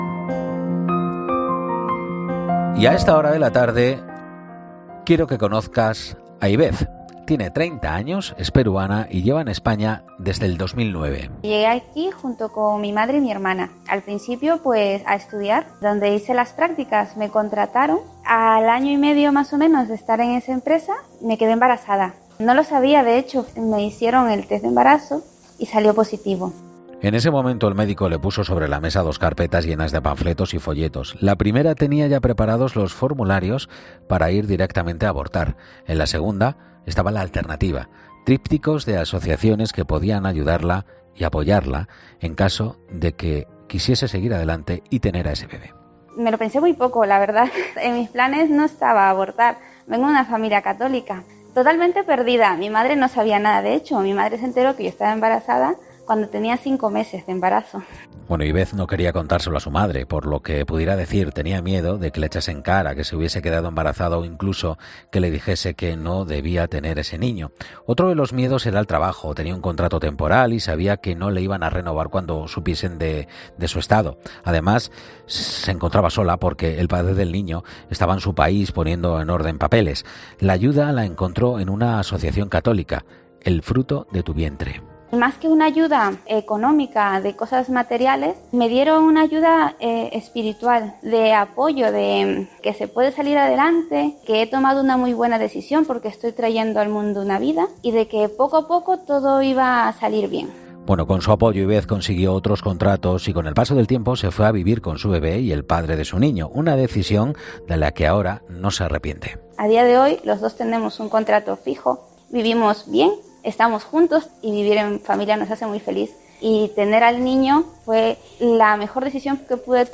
En 'Mediodía COPE' hemos contado con los testimonios de una madre joven, una familia numerosa y una enferma de ELA